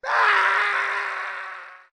Screaming Death 3